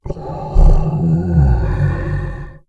MONSTER_Groan_01_mono.wav